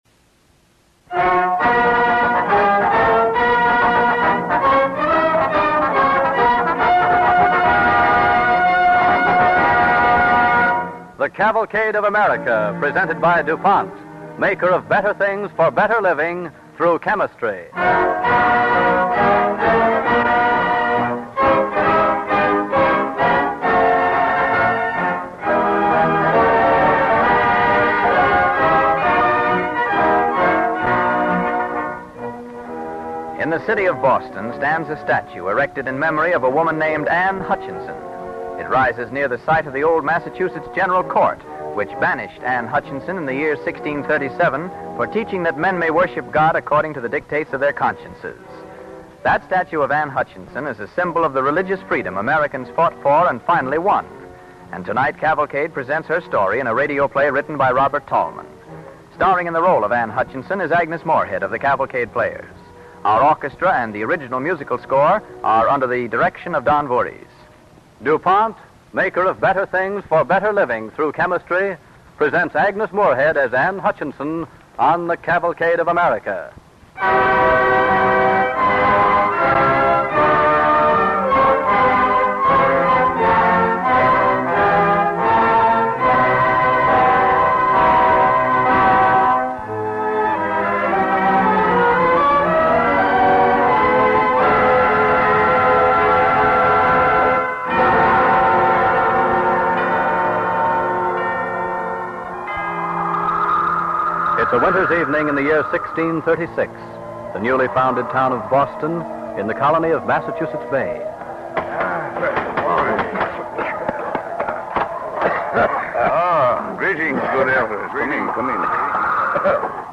With announcer